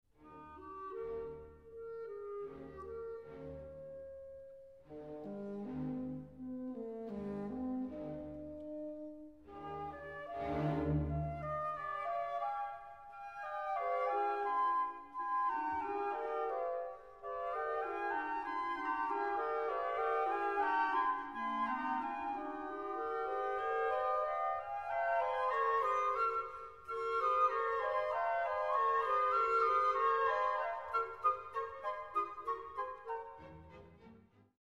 Fascynującą barwę mają instrumenty dęte drewniane. W tym fragmencie w partyturze widnieje oznaczenie dolce:
Sir Roger Norrington, London Classical Players, 1989, I – 6:32 (P), II – 8:48, III – 7:41 (P), IV – 10:47 [33:38], Warner